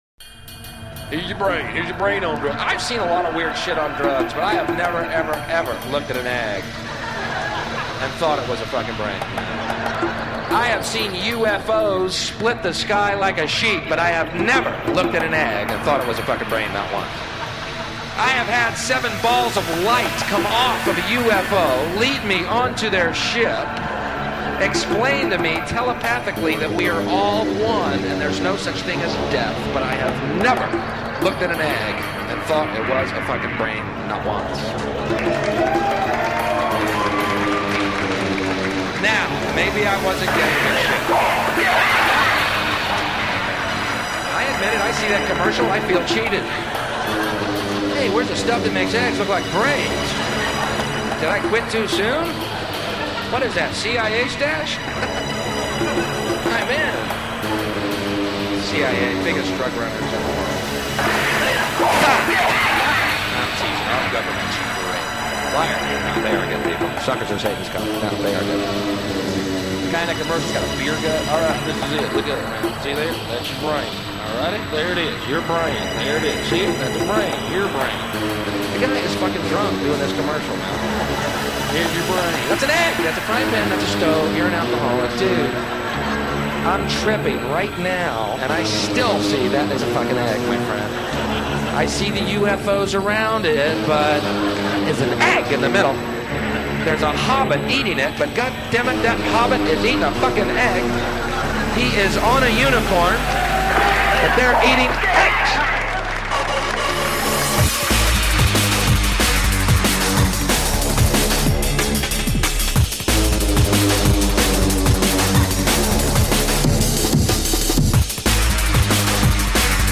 lighten the mood and throw some upliftingv vibes your way